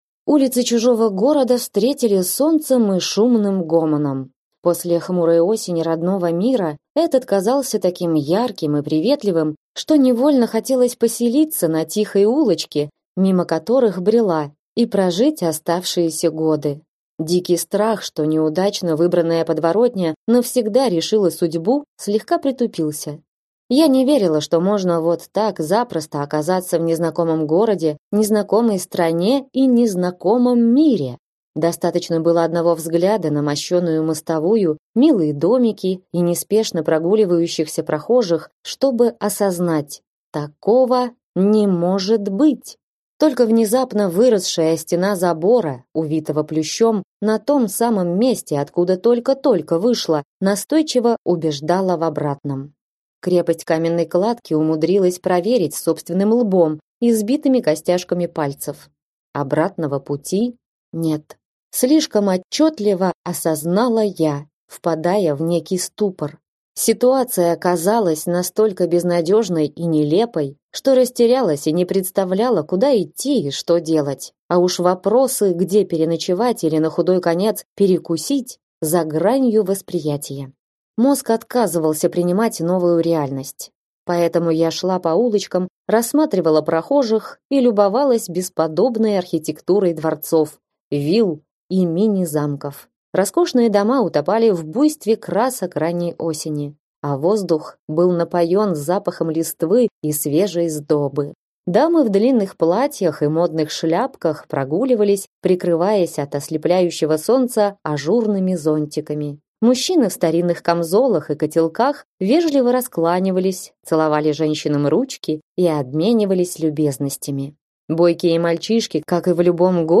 Аудиокнига Сармийская жена | Библиотека аудиокниг
Прослушать и бесплатно скачать фрагмент аудиокниги